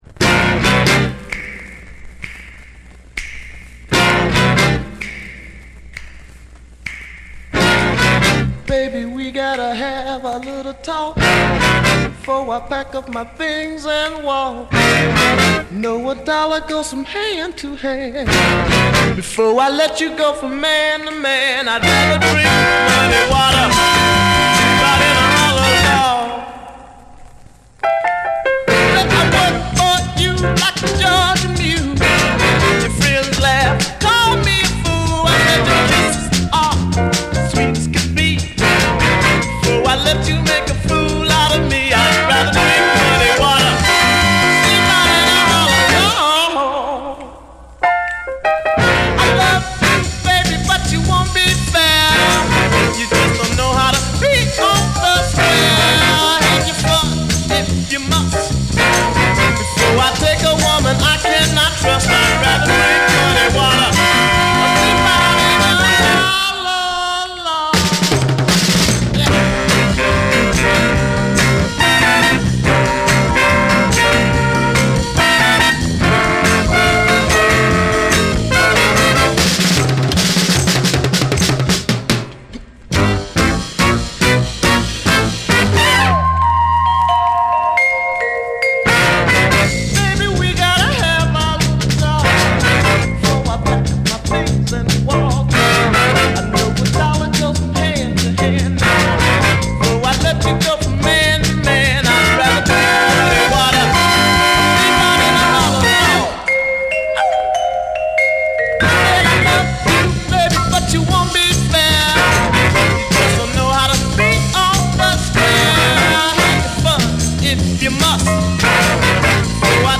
This follow-up 45 is in a much more funky soul vein